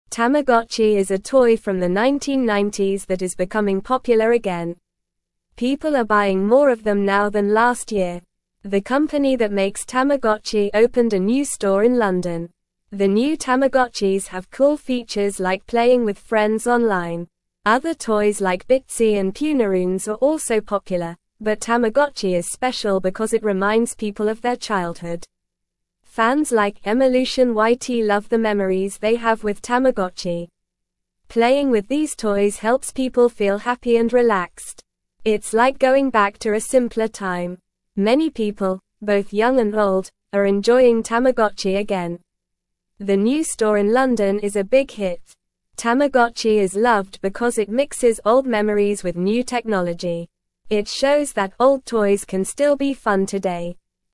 Normal
English-Newsroom-Lower-Intermediate-NORMAL-Reading-Tamagotchi-Toy-Makes-People-Happy-and-Relaxed.mp3